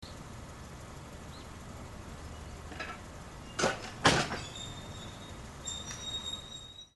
停車中に鵜殿駅の方からチャリン子で走ってきた係員の方が、転轍機を操作してまたどこかへ去って行きました。
転轍機の音